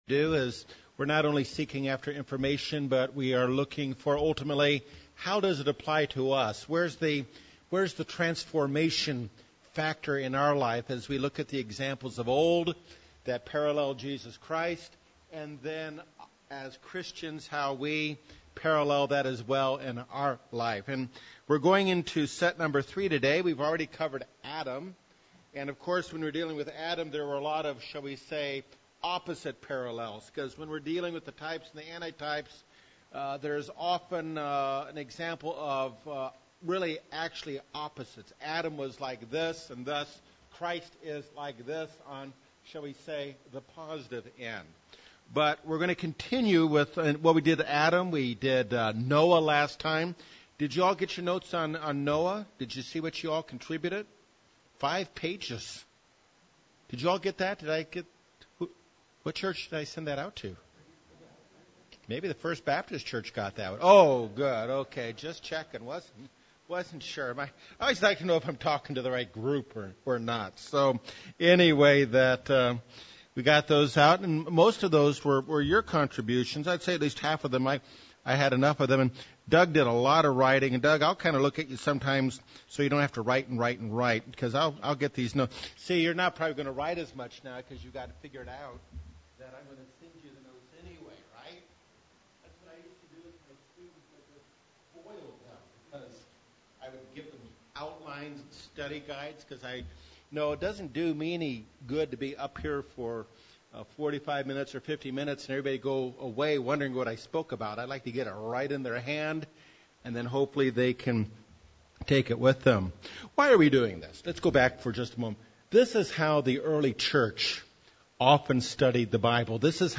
Continuing Bible study on types and anti-types of Christ in the Old Testament. Christ is the key to what mankind has been looking for.